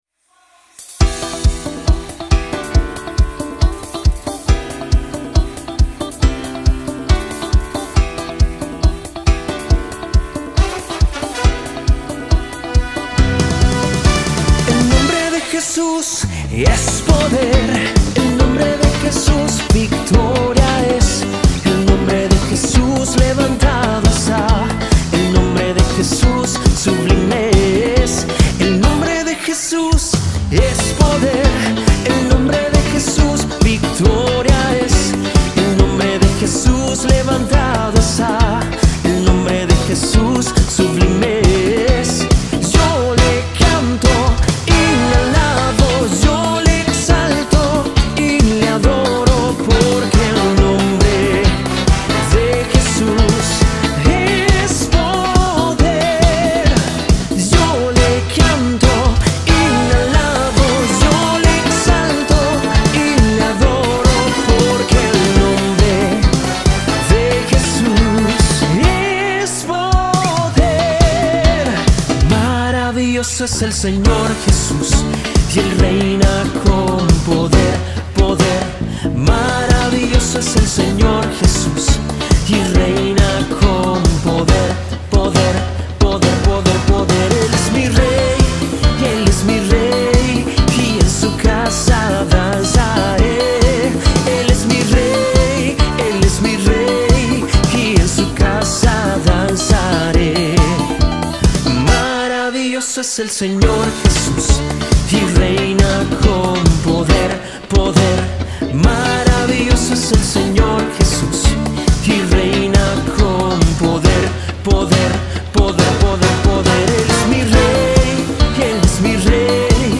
248 просмотров 209 прослушиваний 0 скачиваний BPM: 138